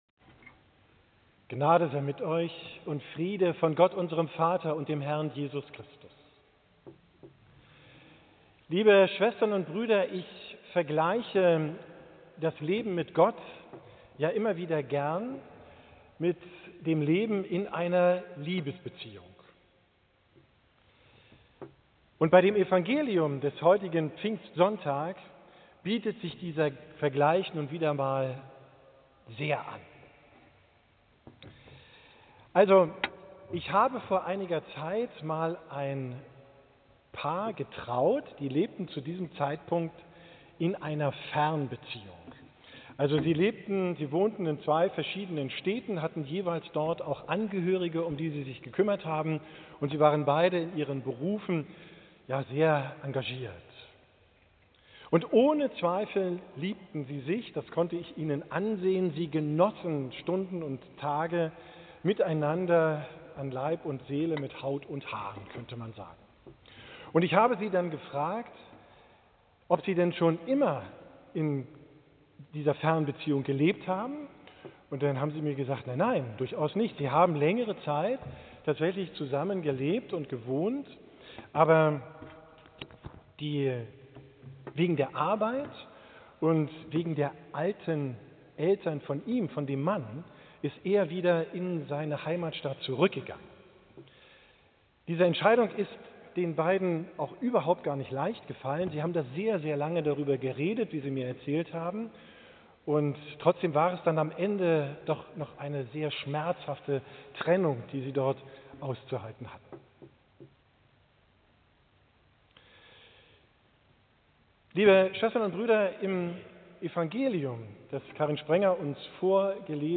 Predigt vom Pfingstsonntag, 8.